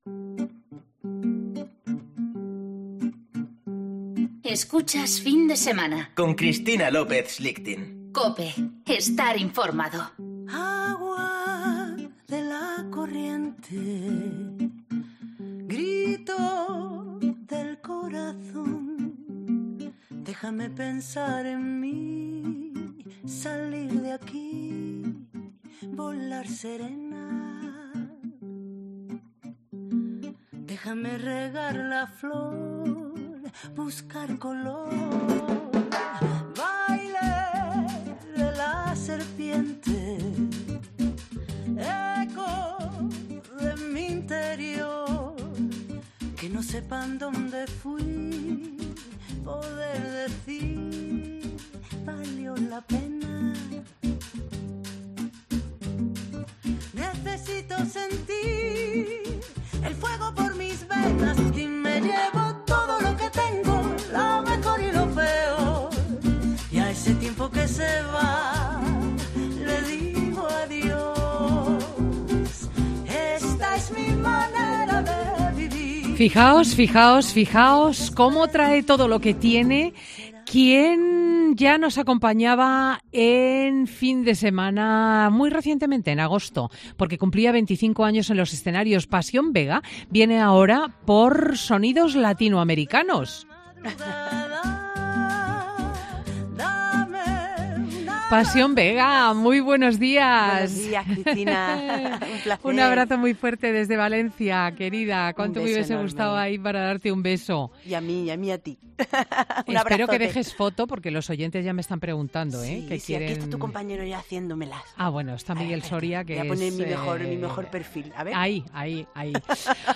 es un magazine que se emite en COPE